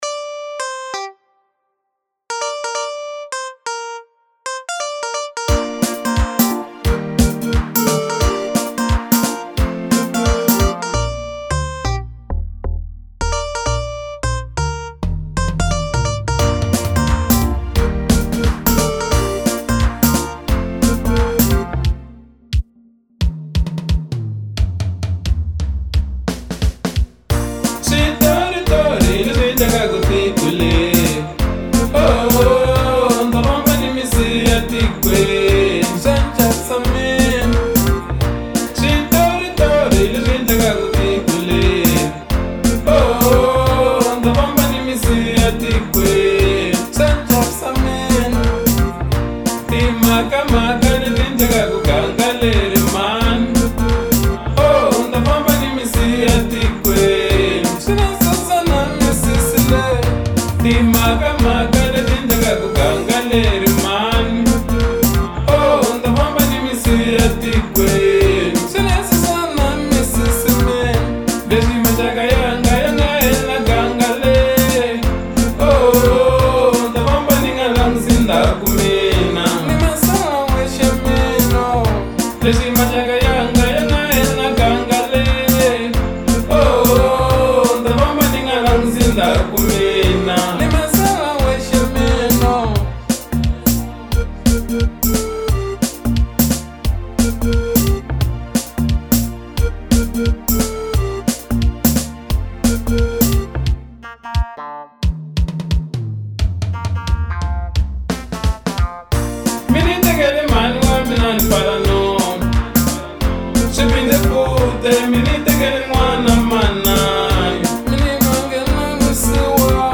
Genre : African Disco